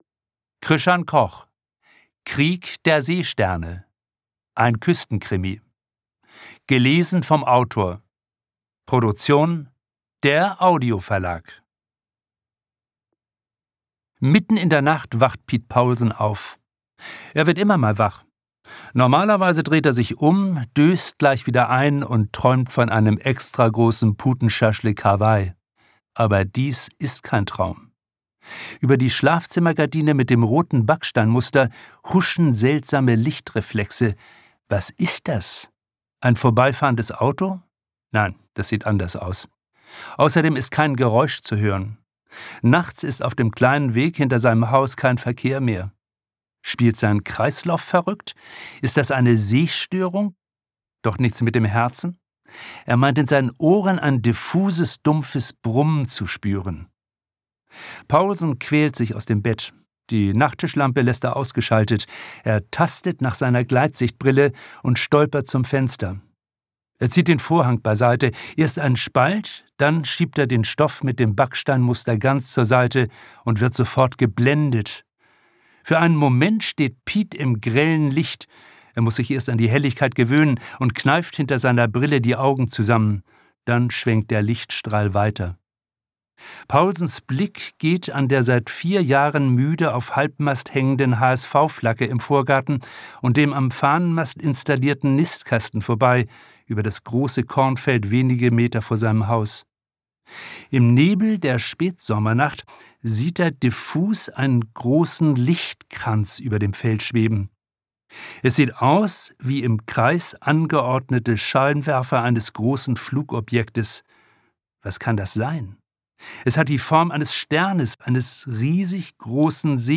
ein Küstenkrimi
Literatur Romane / Erzählungen